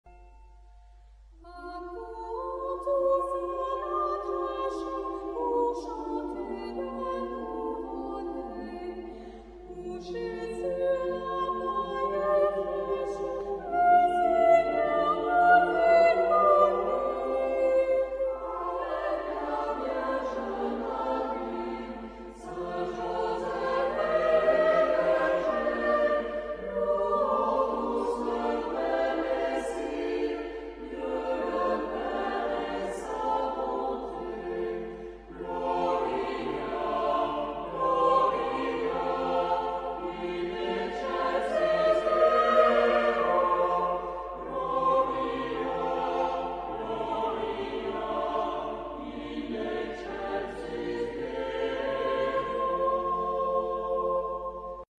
Genre-Style-Forme : Sacré ; noël
Caractère de la pièce : joyeux ; vivant
Type de choeur : SATB  (4 voix mixtes )
Tonalité : fa majeur